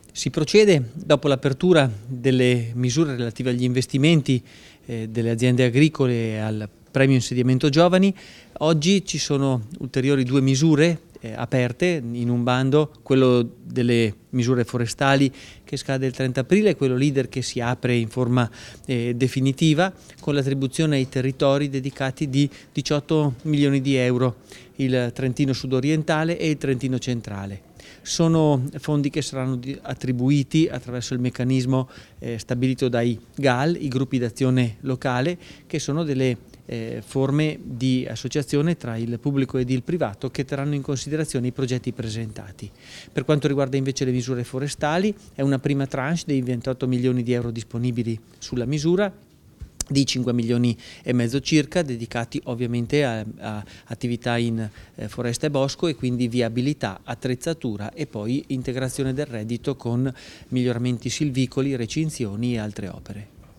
Intervista assessore Dallapiccola